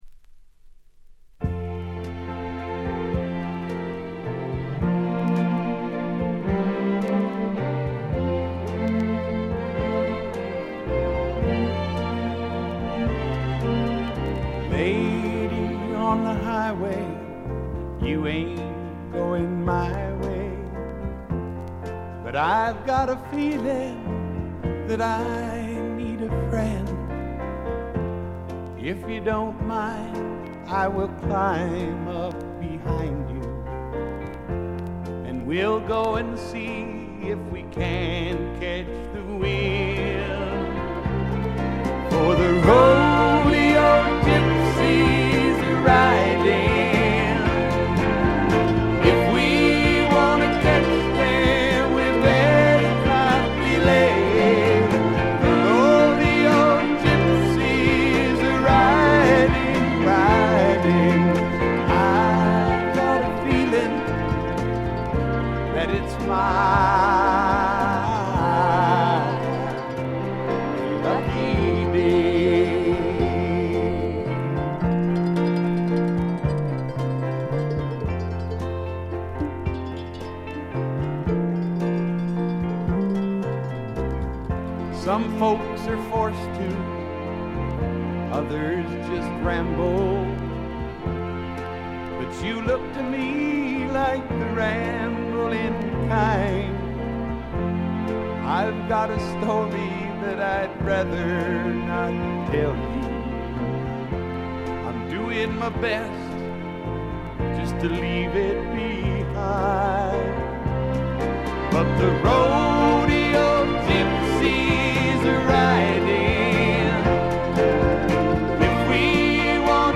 ごくわずかなノイズ感のみ。
スワンプ系シンガーソングライター作品の基本定番。
試聴曲は現品からの取り込み音源です。
Vocals, Acoustic Guitar